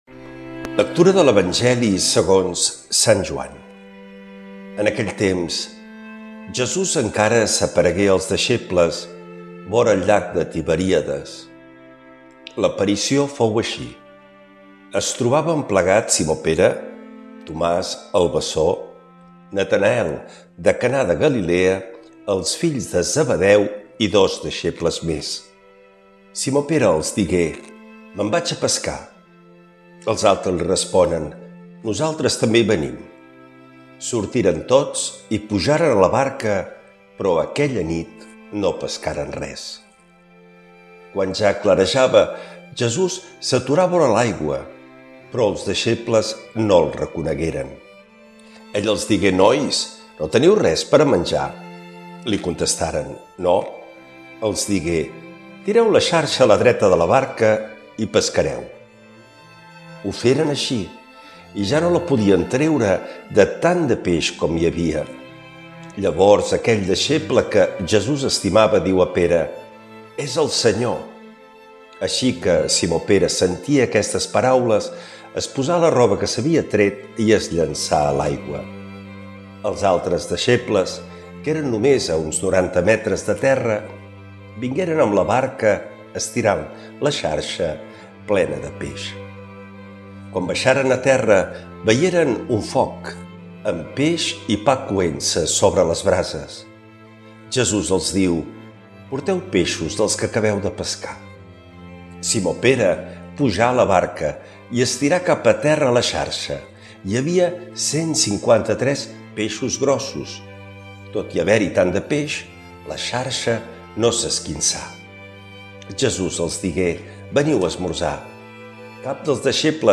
L’Evangeli i el comentari de divendres 10 d’abril del 2026.